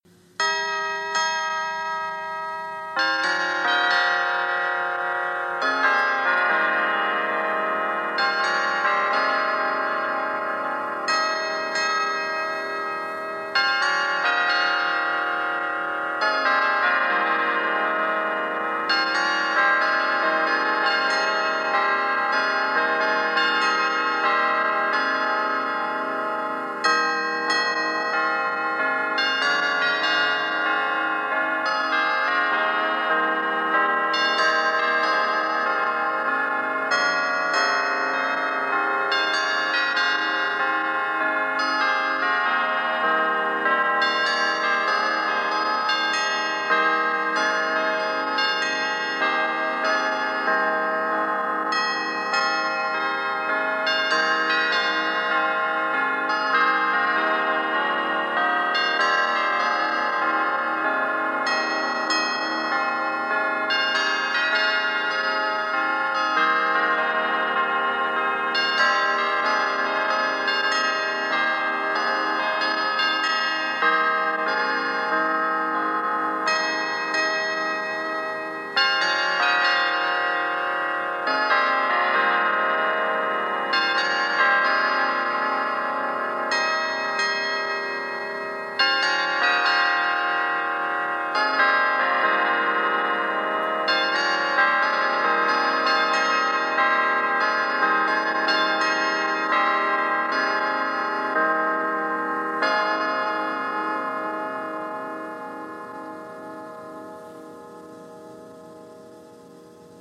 (Listen to the Shepherd carillon play the old fight song)
One particular bell caught my attention last year because it rang out the Westminster Chimes and struck the noon hour—then it played a pretty melody I didn’t recognize.
shepcarillonfightsong.mp3